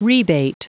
Prononciation du mot rebate en anglais (fichier audio)